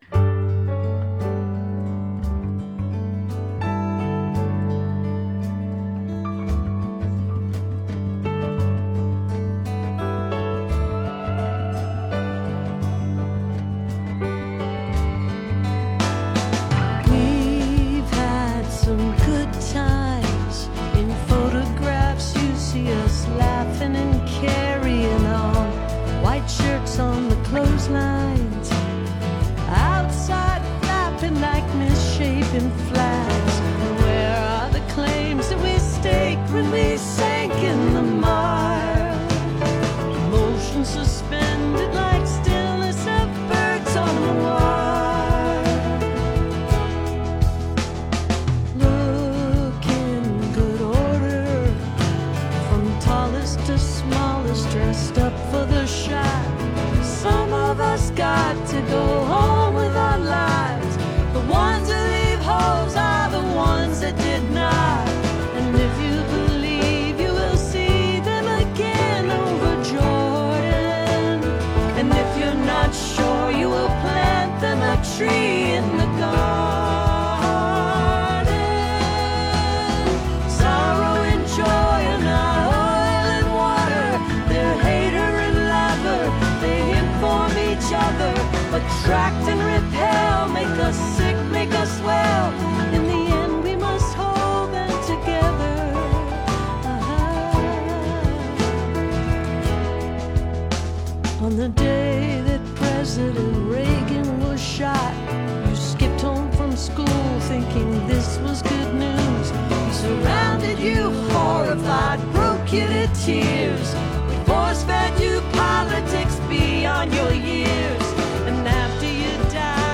(captured from the web broadcast)